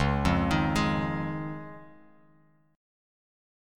C#add9 chord